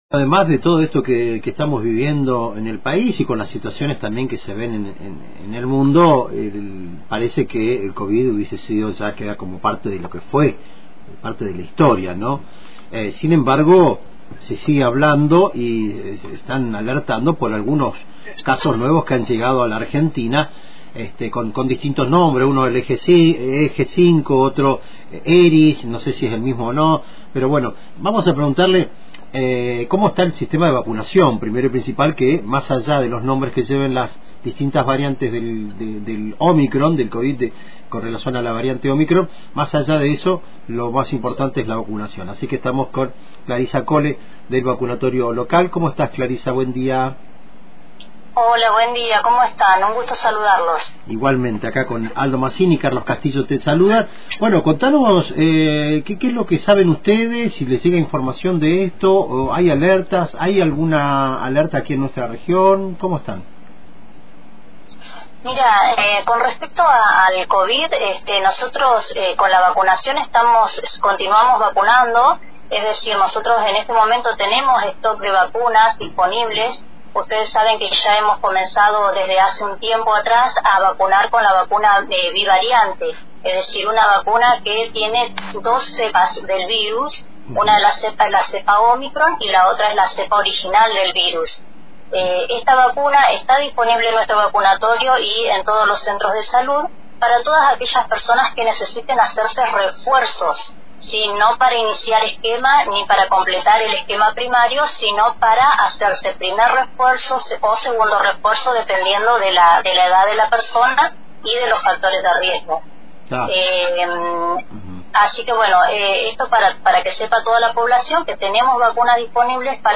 Escucha la entrevista completa acá abajo: